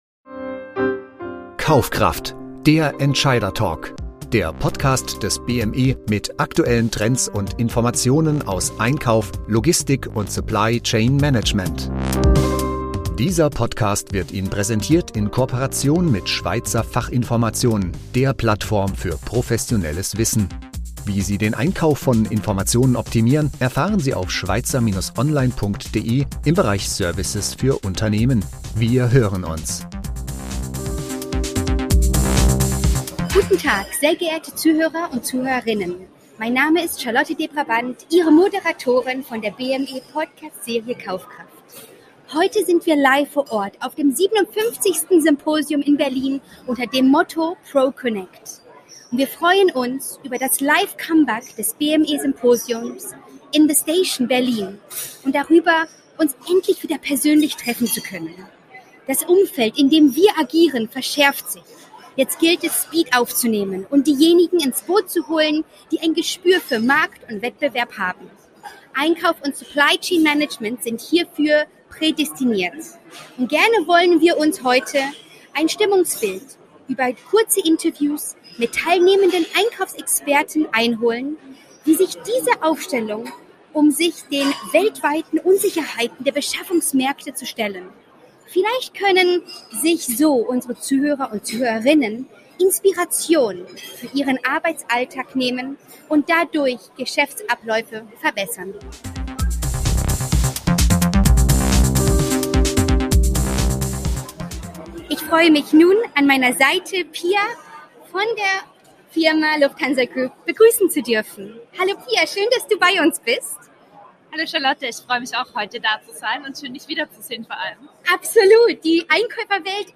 Erfahren Sie in dieser Folge von 7 Procurement-Experten, wie diese sich und Ihre Einkaufsorganisation aufstellen, um sich den weltweiten Unsicherheiten der Beschaffungsmärkte zu stellen.